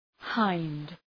Προφορά
{haınd}